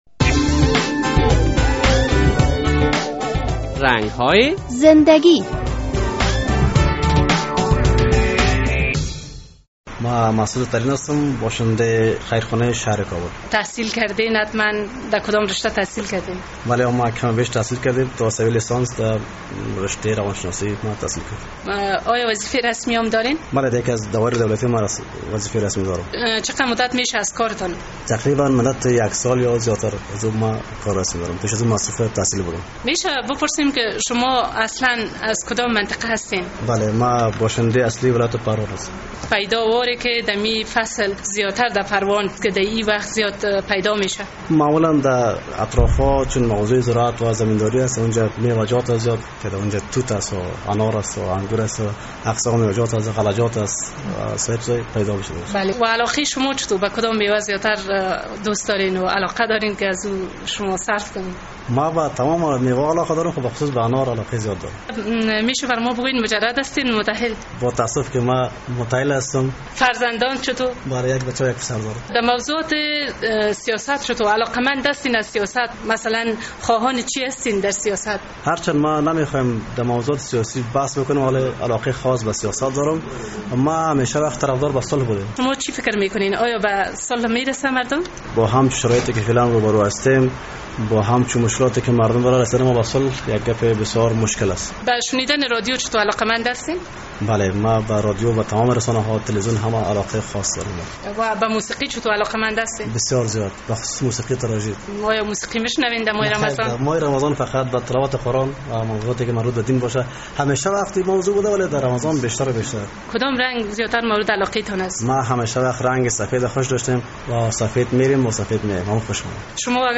در این برنامه با یک تن از کارمندان دولت مصاحبه کرده ایم که در کوهستان تولد شده و در مورد حاصلات میوه شمالی در این فصل با ما سخن گفته است بشنویم:...